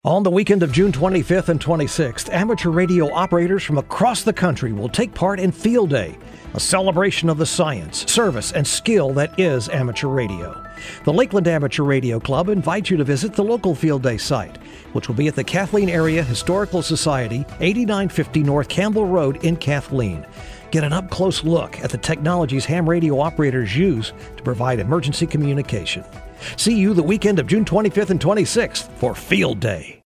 30 second Broadcast radio PSA